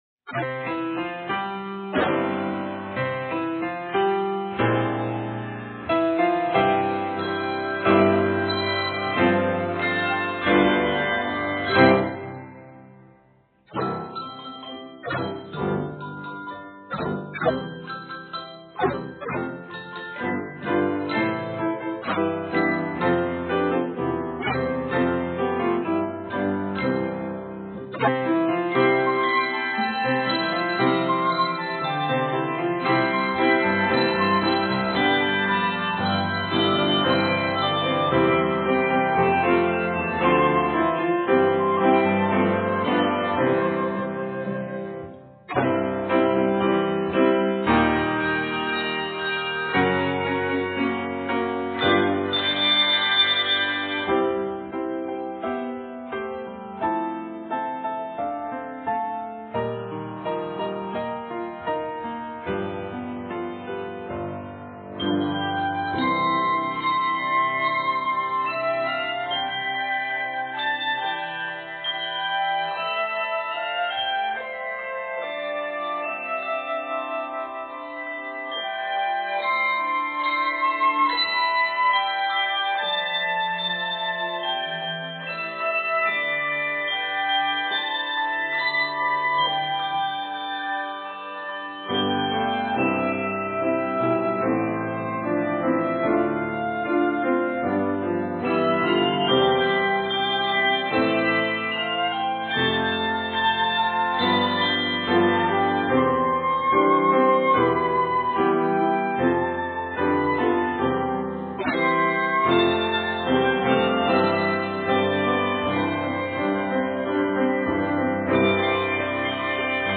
Flute part is included in the handbell score.
Octaves: 3-5